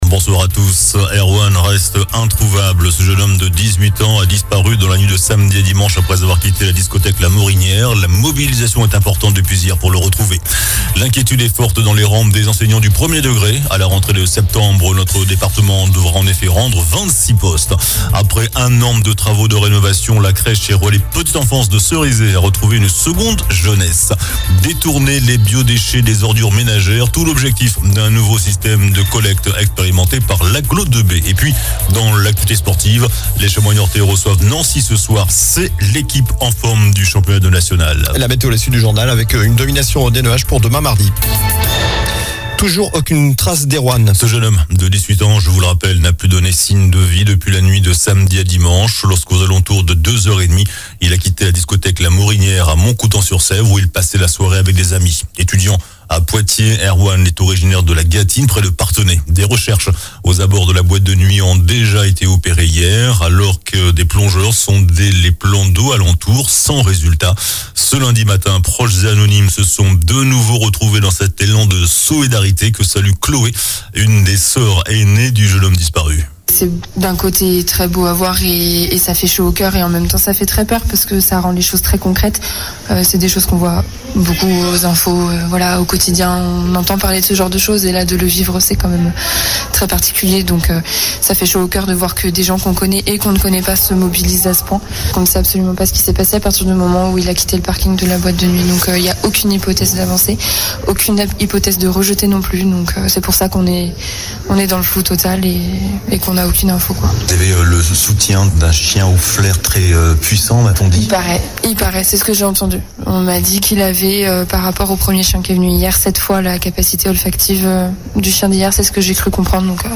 Journal du lundi 12 février (soir)